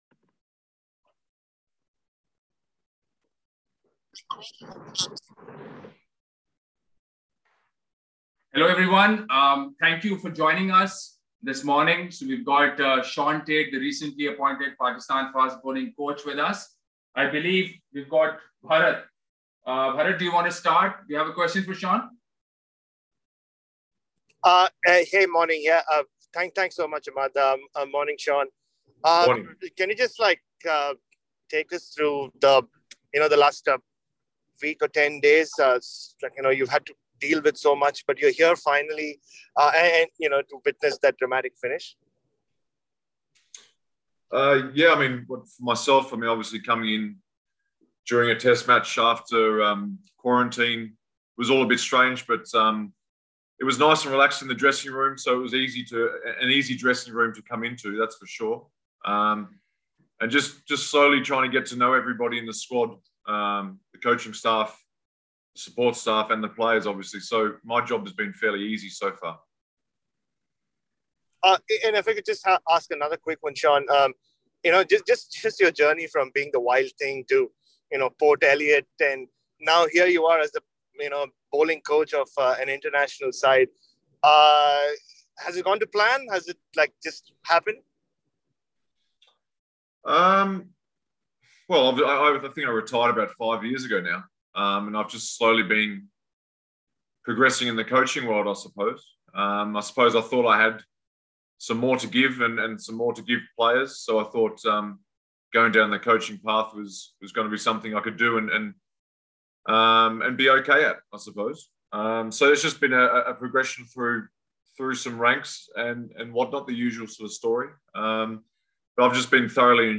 Pakistan bowling coach Shaun Tait held an online press conference today (Saturday). Pakistan and Australia will begin preparations for the third and final Benaud-Qadir Trophy, ICC World Test Championship match from today at the Gaddafi Stadium, Lahore.